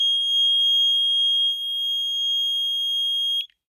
На этой странице собраны звуки металлодетекторов — от стандартных сигналов до вариаций при обнаружении разных металлов.
Арочный металлодетектор издает звук при обнаружении металла на входе